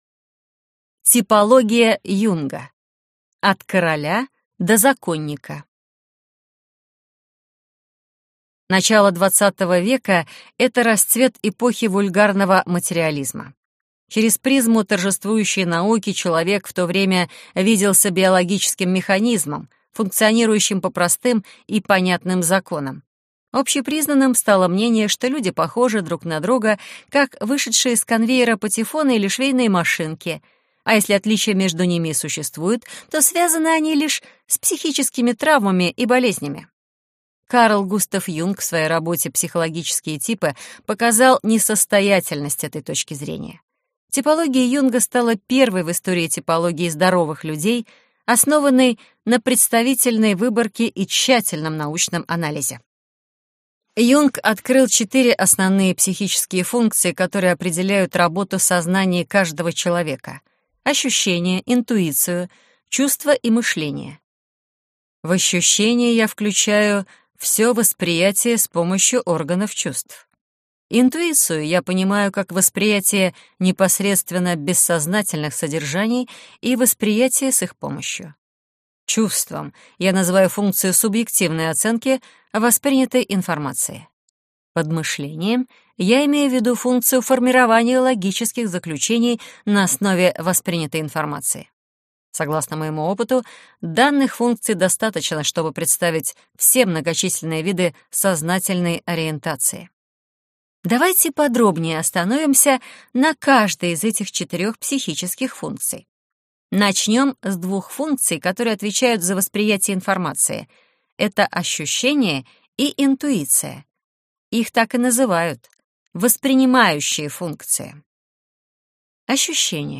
Аудиокнига От соционики до теории уровней: восемь самых интересных типологий | Библиотека аудиокниг